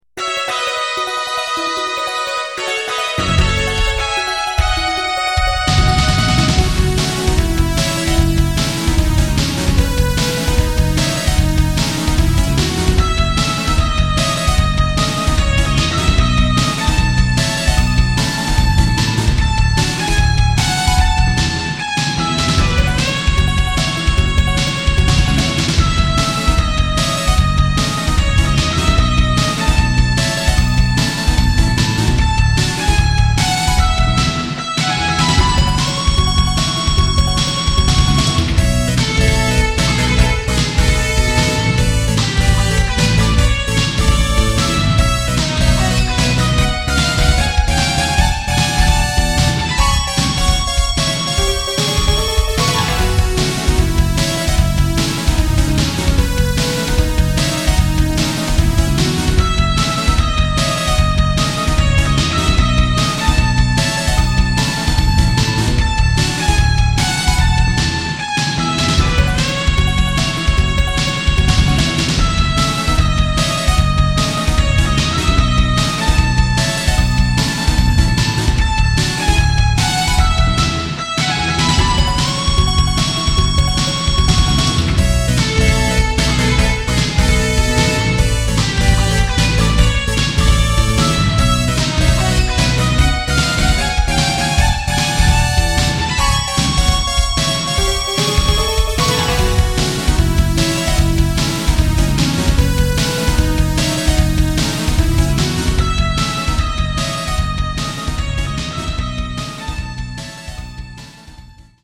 SC88Pro